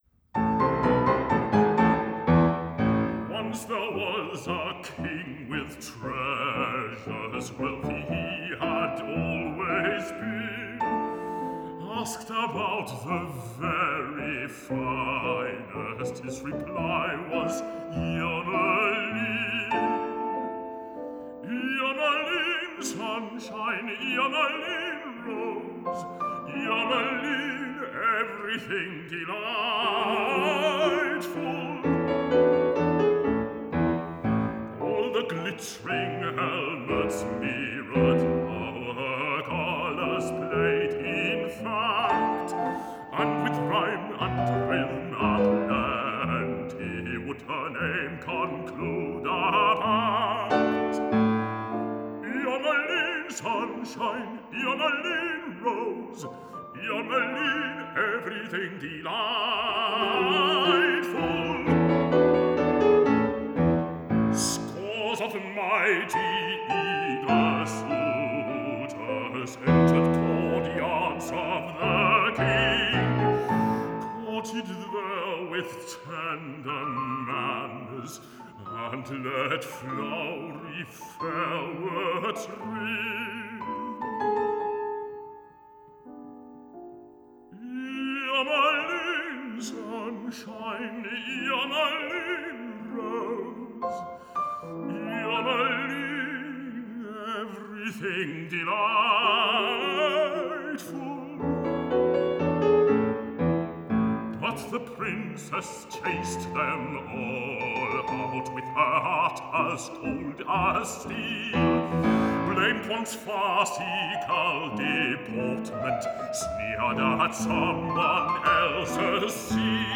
Baryton
Piano